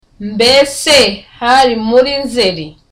Dialogue
(Relaxed)